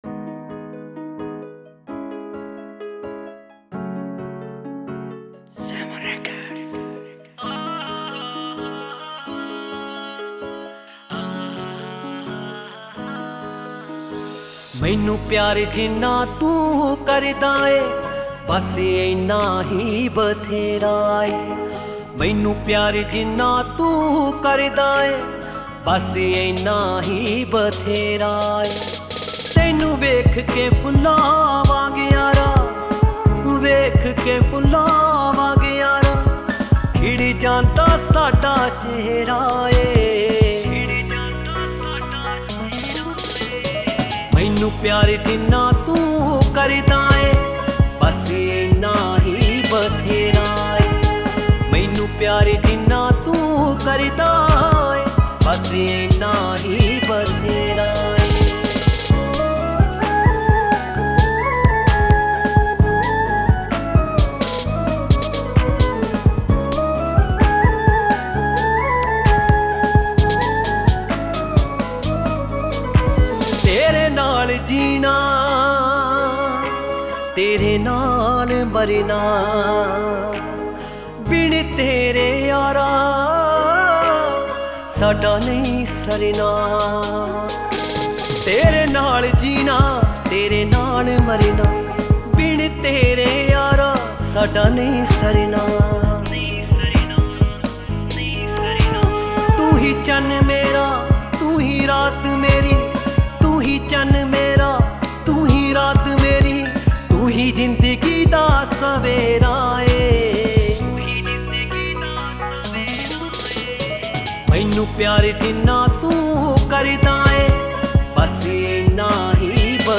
Genre Punjabi Old Song